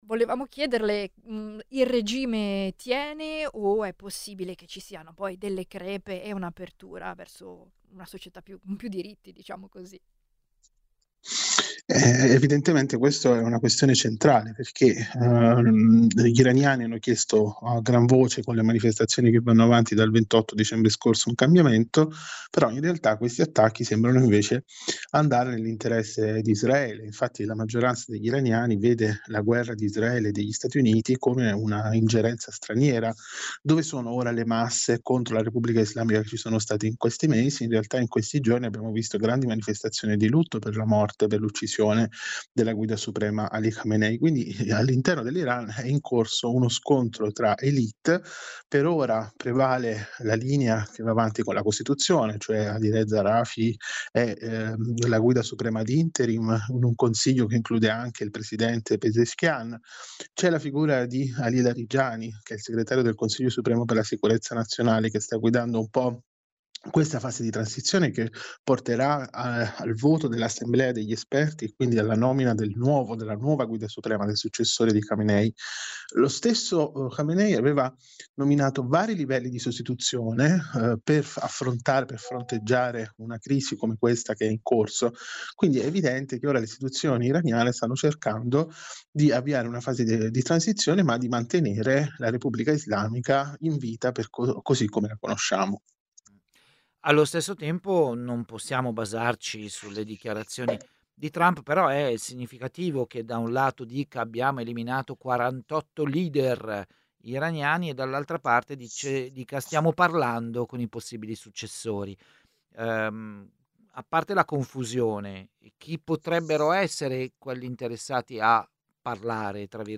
Nella trasmissione Presto Presto abbiamo fatto due interviste di approfondimento su quello che sta accadendo in Iran e, in generale, in Medio Oriente.